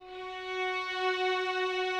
strings_054.wav